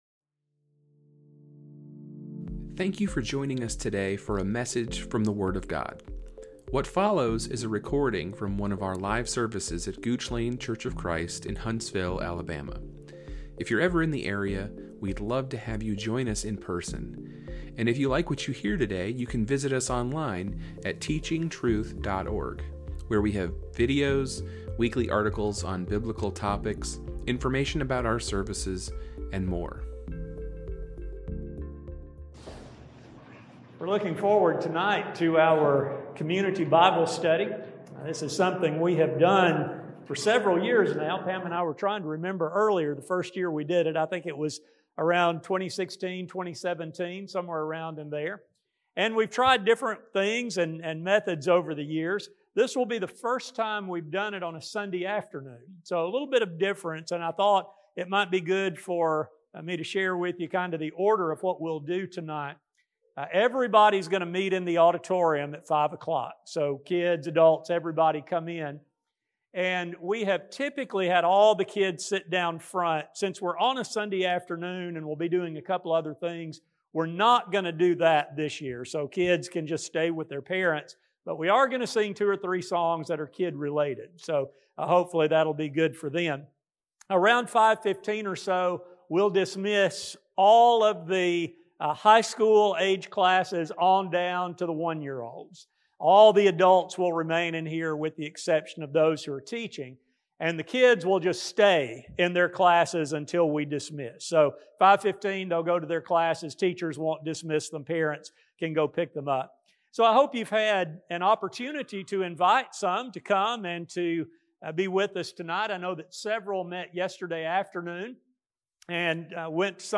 Gooch Lane Church of Christ Podcast